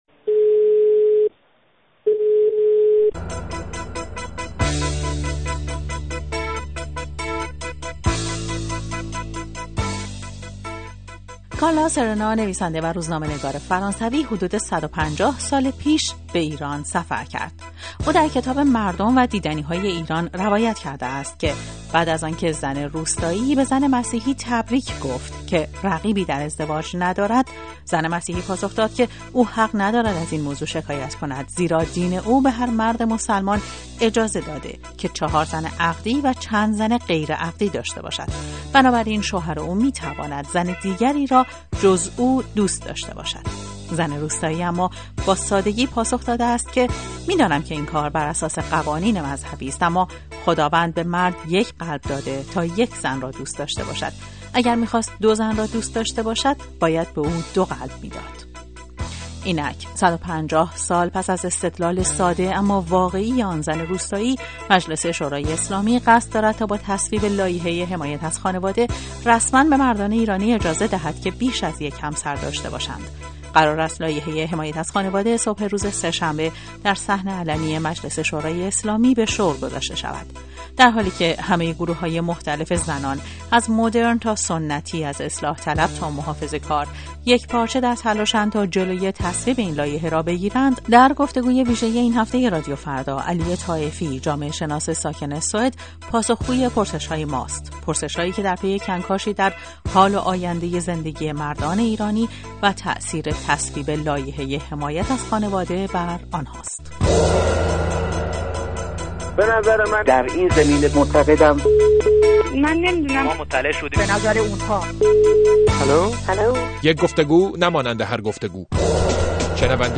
گفت و گوی ویژه